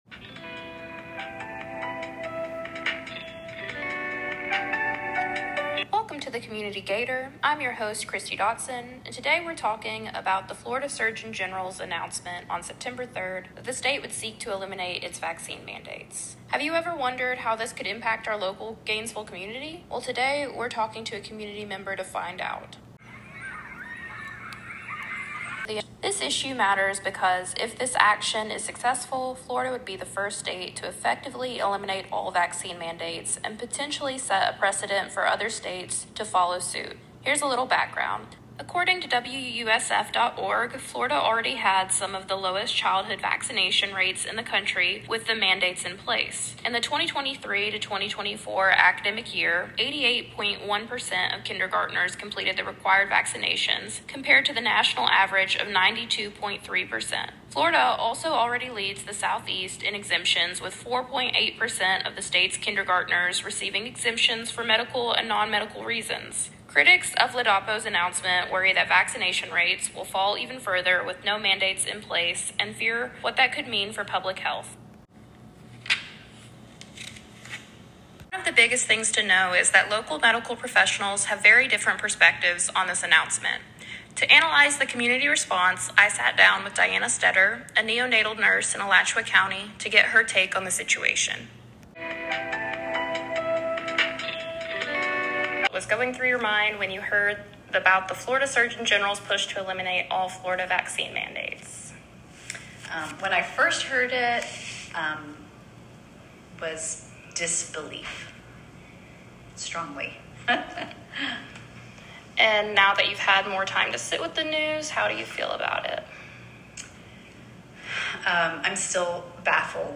Podcast Interview
Audio interview with a local nurse